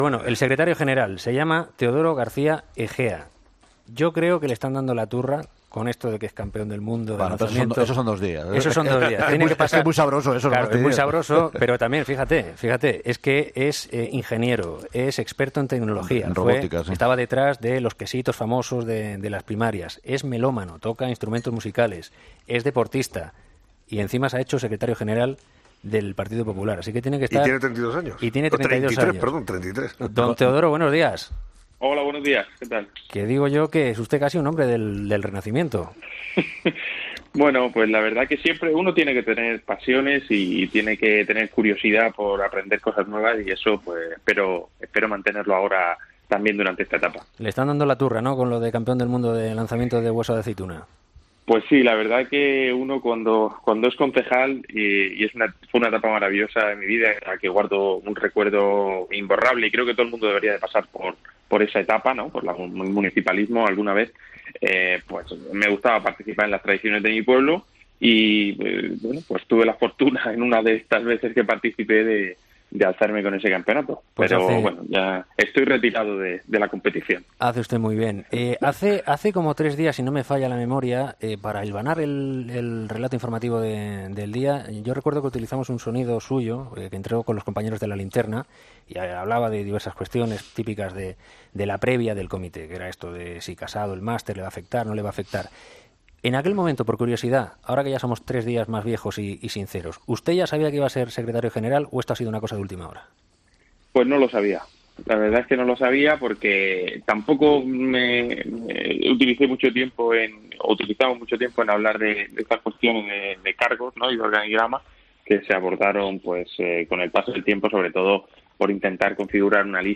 El nuevo secretario general del Partido Popular, Teodoro García Egea, ha participado en 'Herrera en COPE' para comentar la situación actual del PP, tras el congreso y las negociaciones para integrar la lista de Soraya Sáenz de Santamaría, y cuáles son los próximos pasos que dará el partido en política nacional.